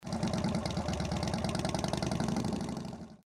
harley-idle.Bhyc5L28.mp3